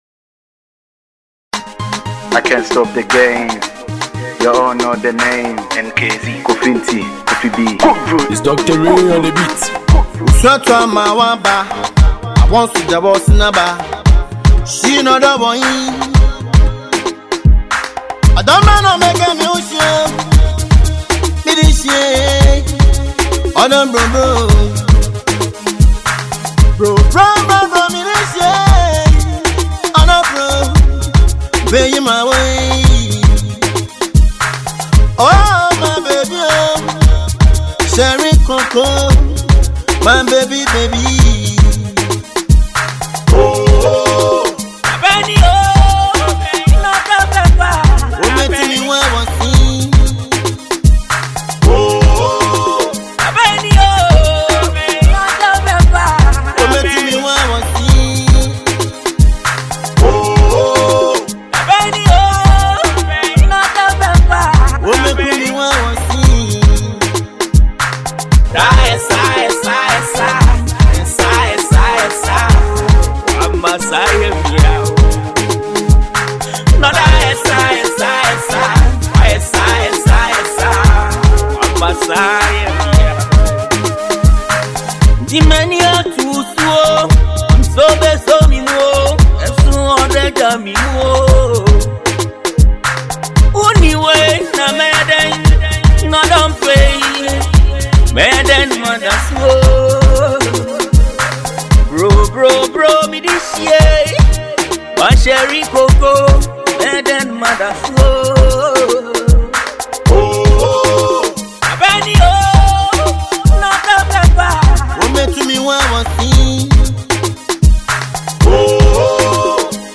Ghana Music
highlife